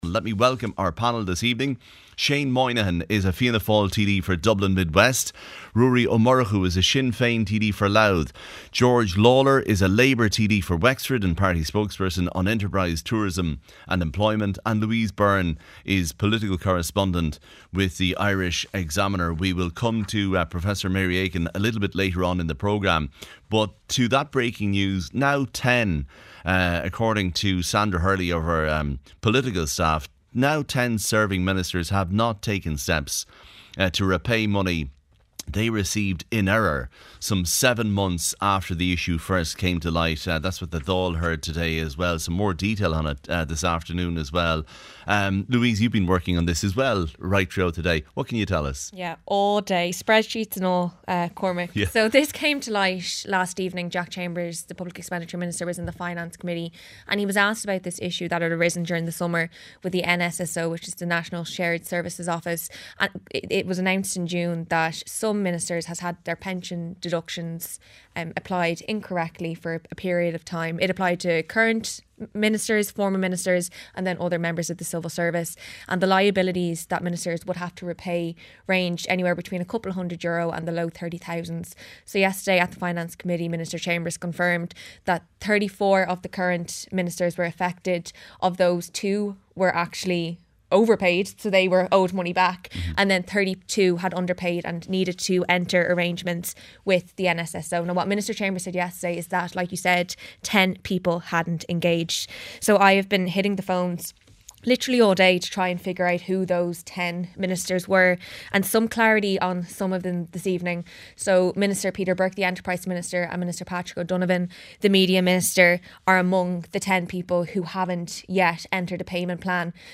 The Late Debate panel